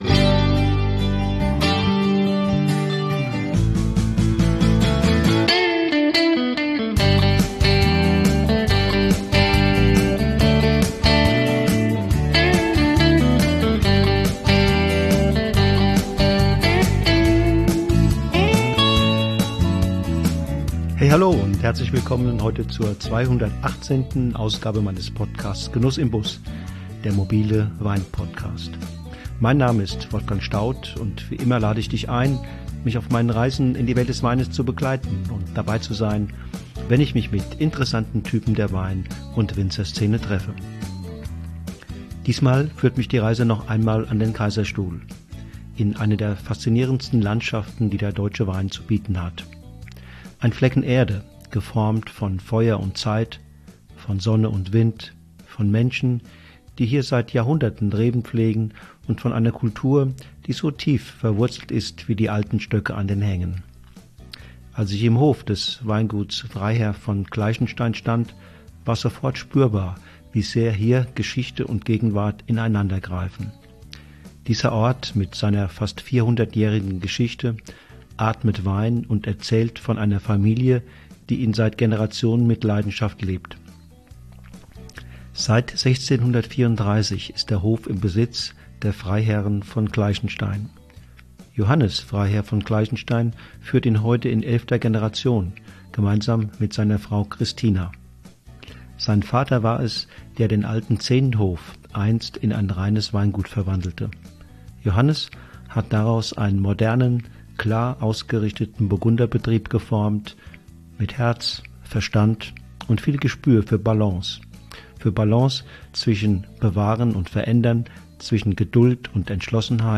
Ein leises, intensives Gespräch über Wein, der aus Feuer kommt – und aus Zeit entsteht.